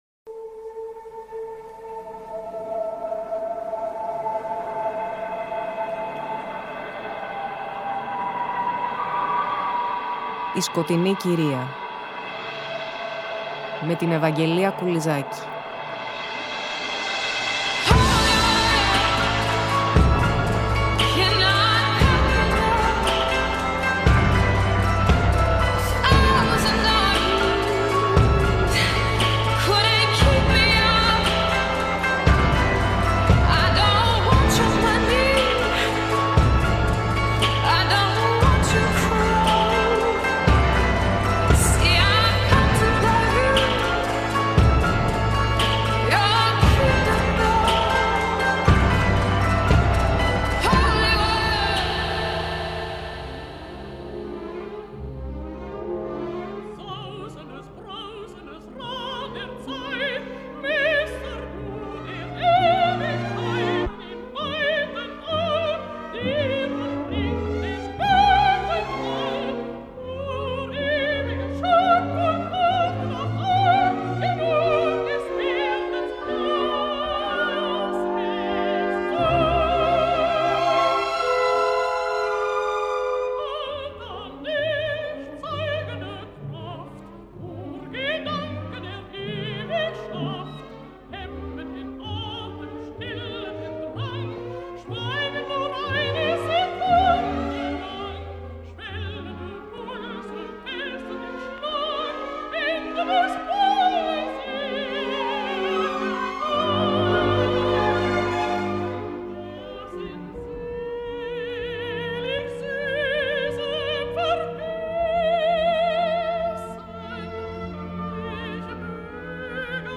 Πλαισιώνουμε μουσικά με τα βαγκνερικά lieder του κύκλου Wesendonck, ερμηνευμένα από την Kirsten Flagstad.